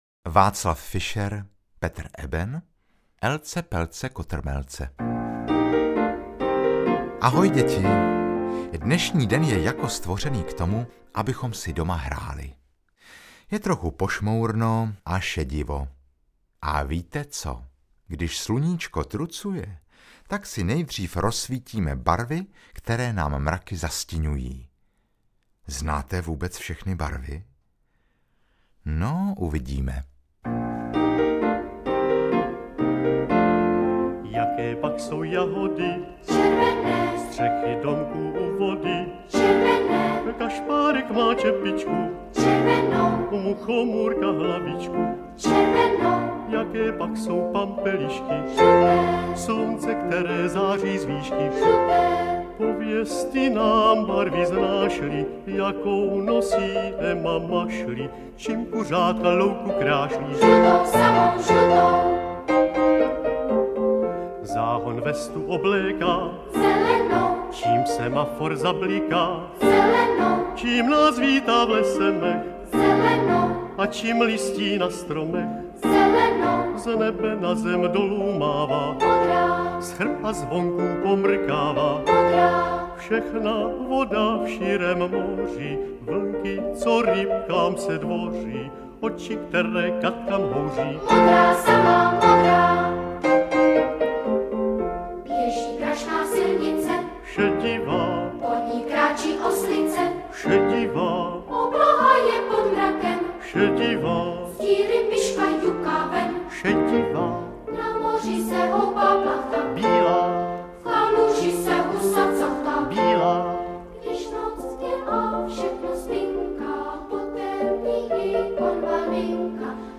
Veselé písničky s hravým cvičením pro děti malé i větší!